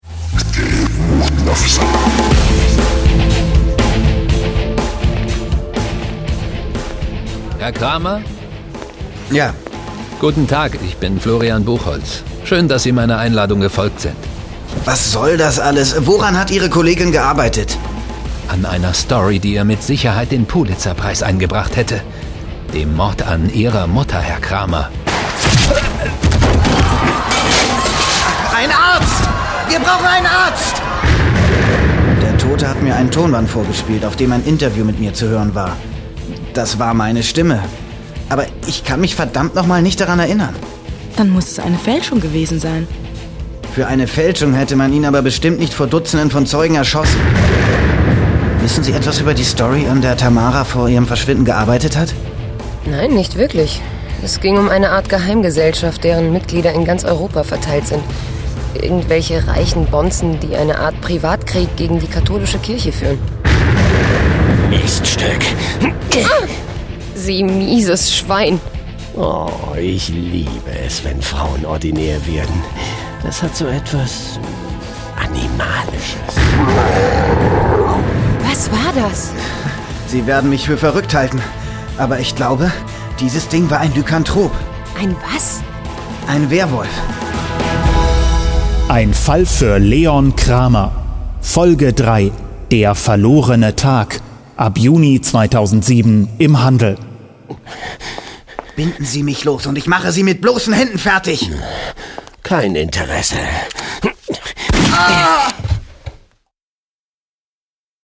Sprecher: Fabian Harloff,